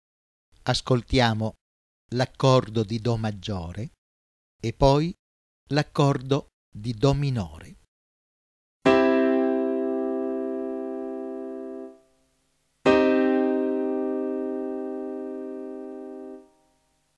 Do-Mi-Sol:   accordo Maggiore
Do-Mib-Sol: accordo minore
ascolto dell’accordo Maggiore e dell’accordo minore.